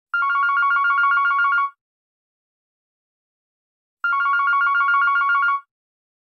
6. digital ringtone